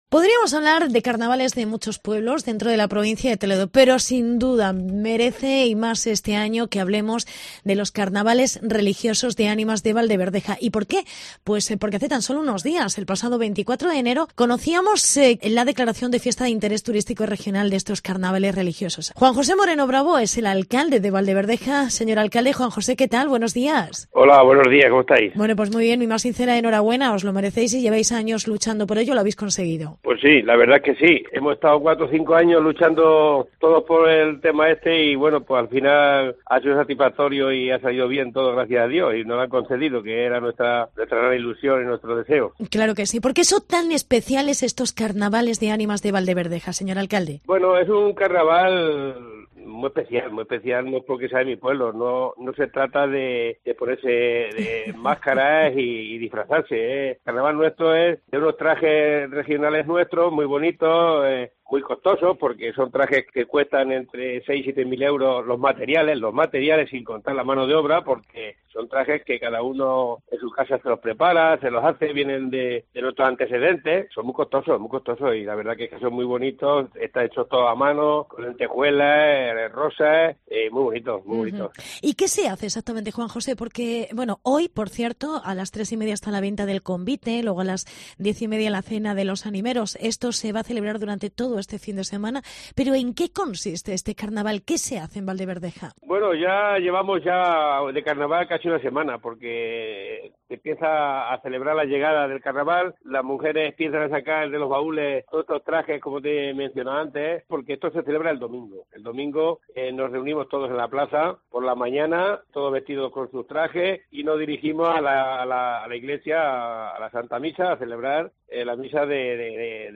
Entrevista con el alcalde. Juan José Moreno Bravo